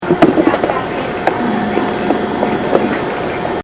Here's another version without noise filtering. Lots of hiss and rumble, but no weird tones, just volume.
There's a noticeable amount of high range fidelity or "crispness" in the original that suggests the source was very close, perhaps 20 feet away or less.
Sounds a bit too much like intelligent human speech.
snap_taffy_loud.mp3